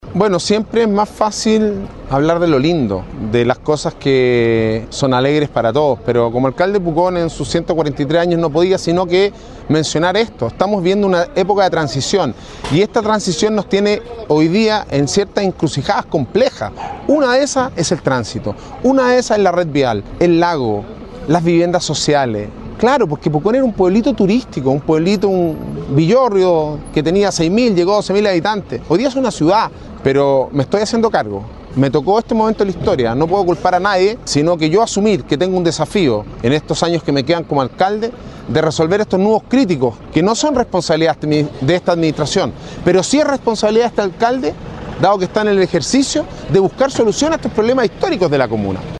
Cientos de asistentes llegaron el viernes último al frontis de la Municipalidad de Pucón, en donde prácticamente todas las fuerzas vivas de la urbe lacustre se reunieron para festejar los 143 años de existencia de esta reconocida urbe turística en un desfile cívico, del que fueron parte más de 40 organizaciones.
Alcalde-Sebastian-Alvarez-dice-enfrentar-los-problemas-en-estos-143-anos-.mp3